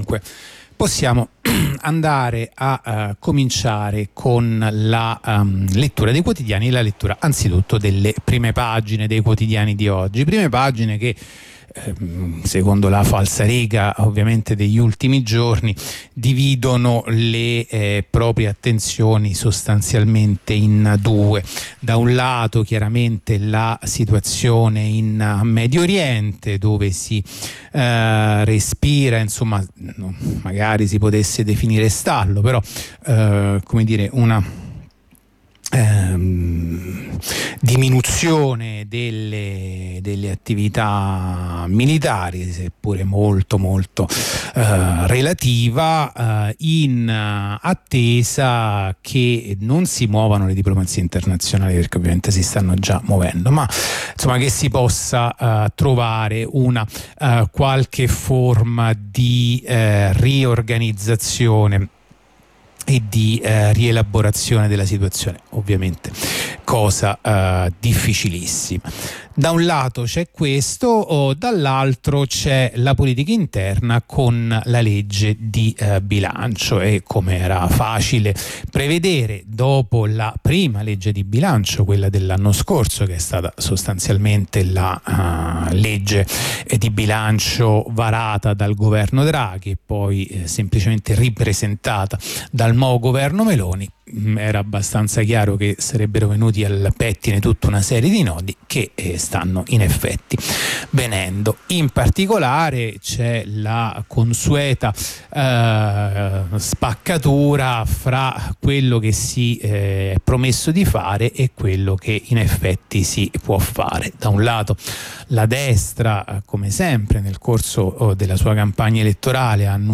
La rassegna stampa di radio onda rossa andata in onda venerdì 27 ottobre 2023